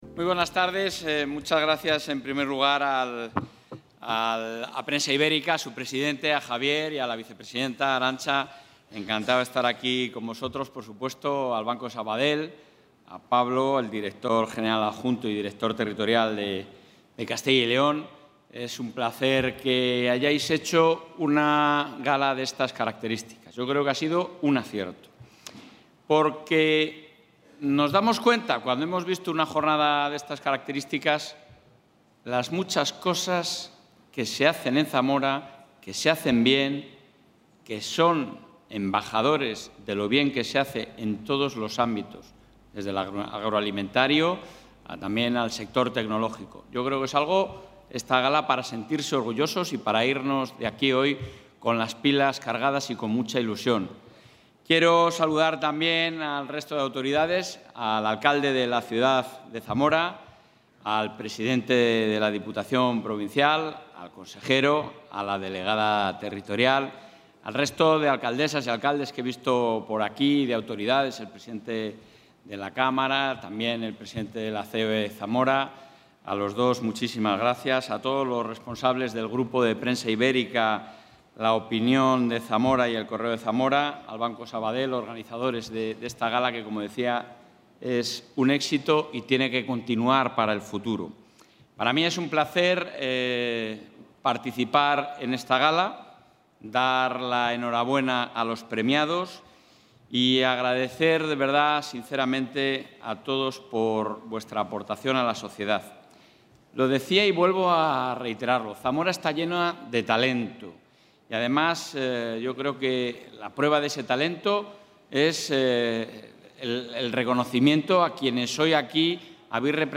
El presidente de la Junta de Castilla y León, Alfonso Fernández Mañueco, ha participado hoy en el acto de entrega de los Premios...
Intervención del presidente de la Junta.